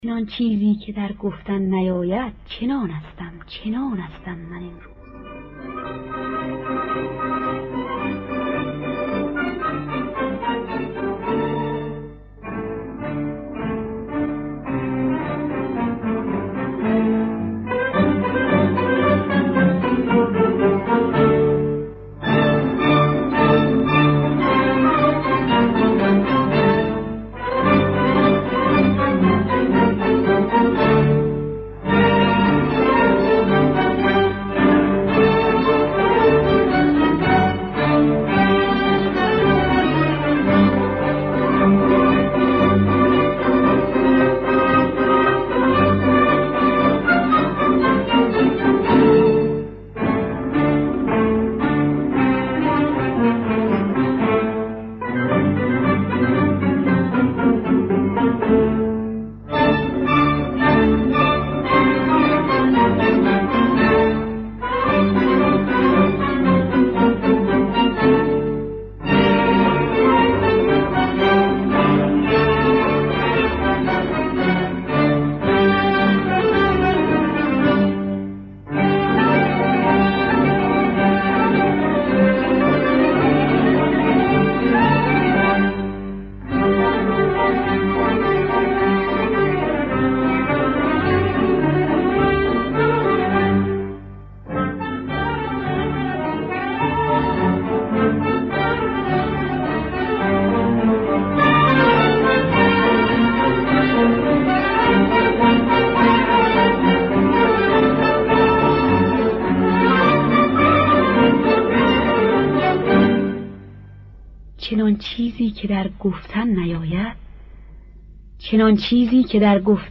در دستگاه بیات اصفهان